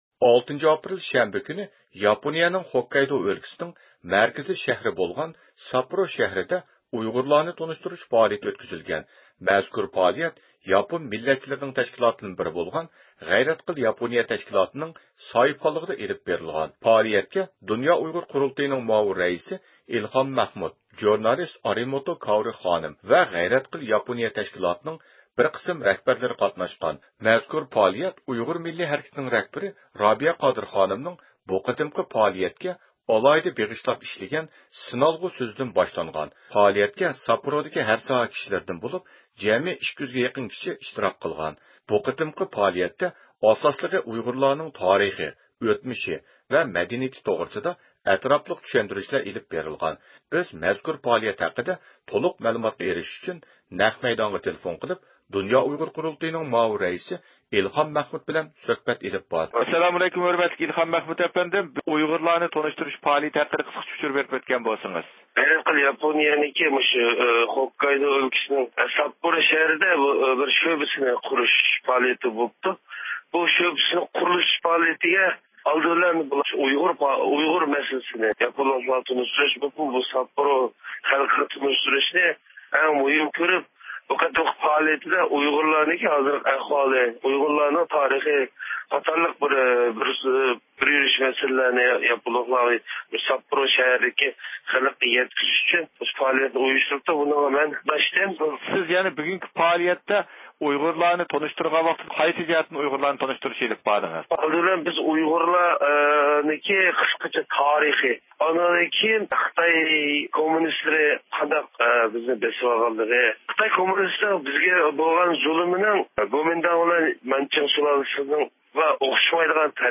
بىز مەزكۇر پائالىيەت ھەققىدە تولۇق مەلۇماتقا ئېرىشىش ئۈچۈن نەق مەيدانغا تېلېفون قىلىپ